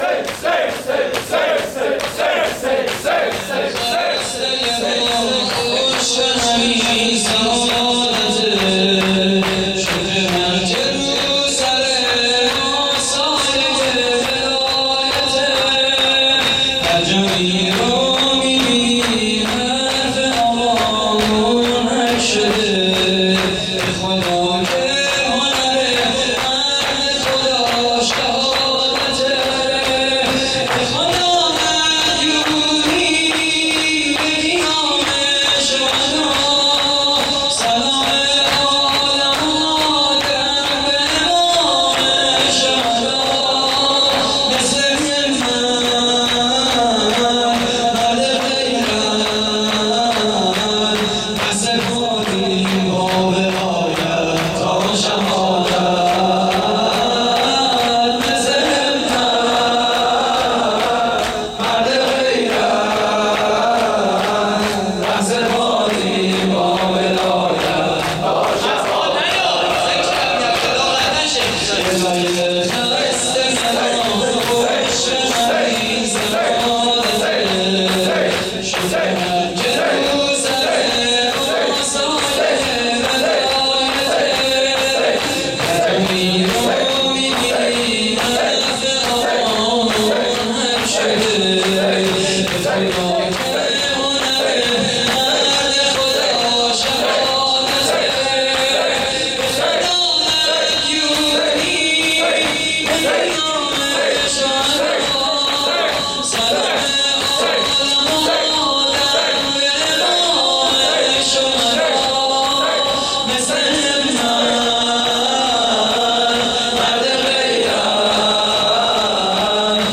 مداحی شب 23 رمضان (عزاداری و احیای شب قدر) / هیئت کریم آل طاها (ع)؛ 28 خرداد 96
صوت مراسم:
شور: هنر مرد خدا شهادته؛ پخش آنلاین |